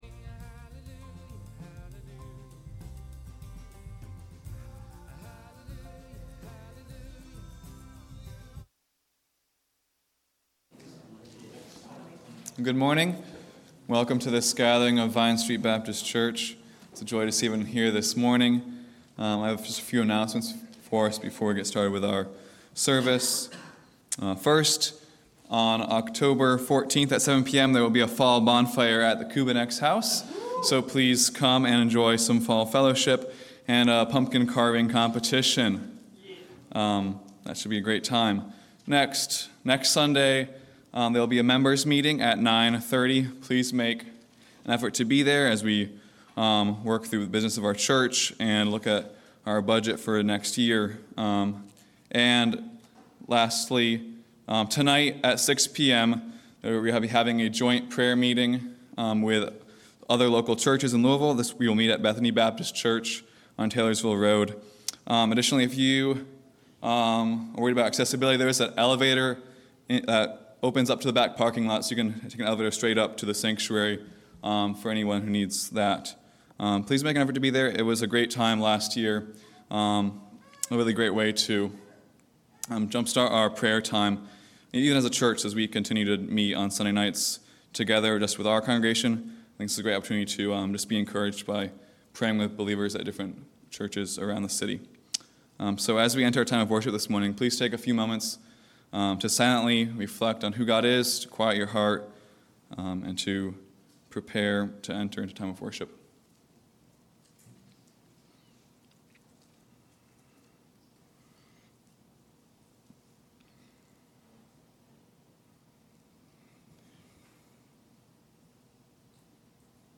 October 1 Worship Audio – Full Service